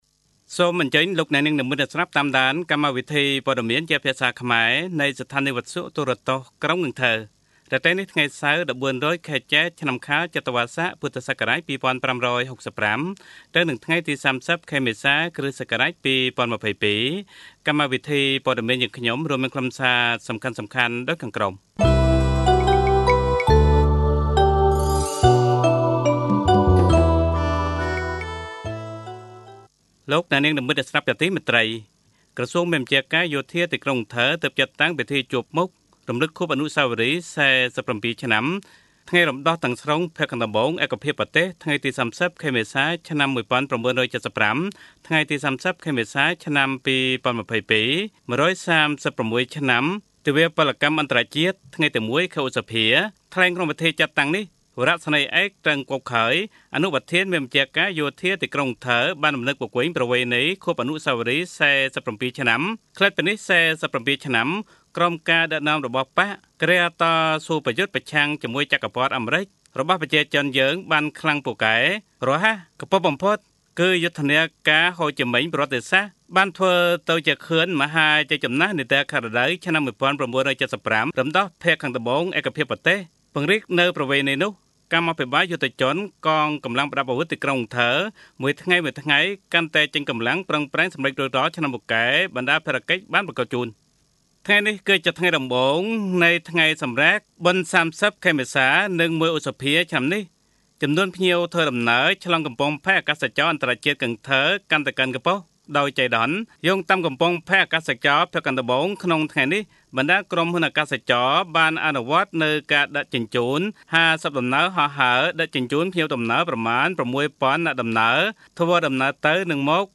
Bản tin tiếng Khmer tối 30/4/2022